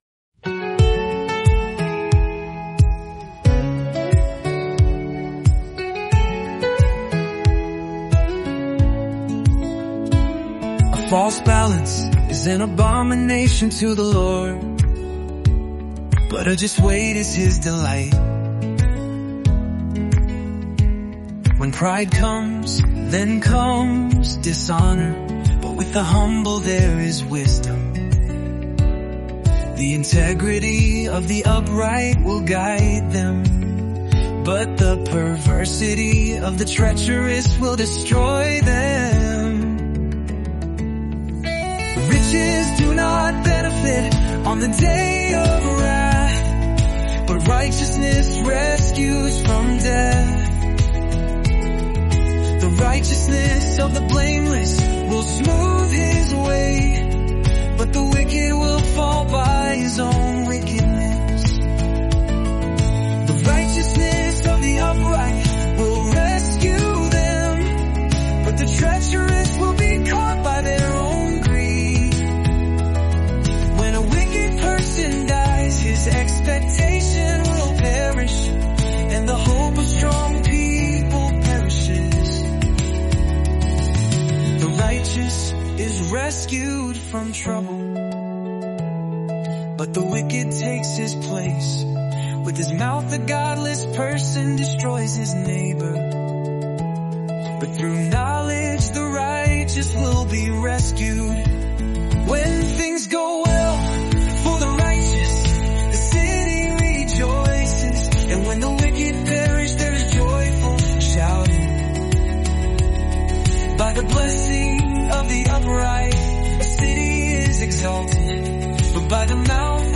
Immerse yourself in the timeless wisdom of Proverbs in just 31 days through word-for-word Scripture songs.